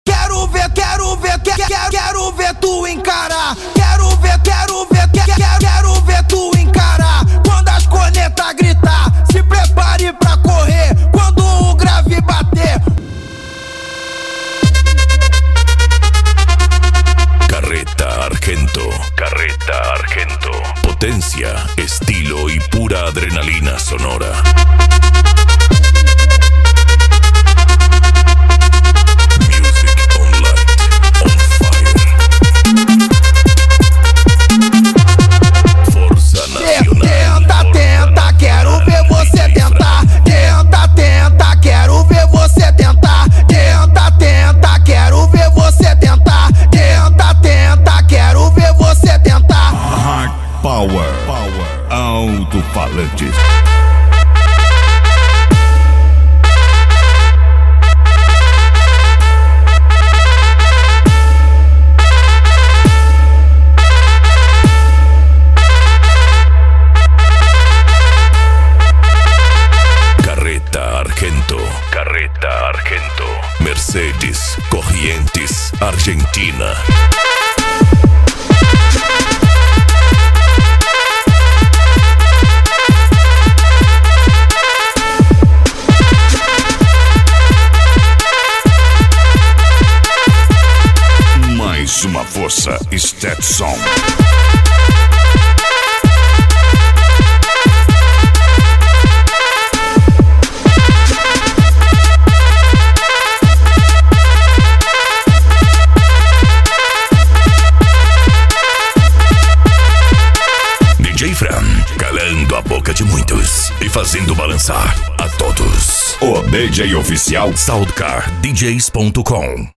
Bass
Psy Trance
Racha De Som
Remix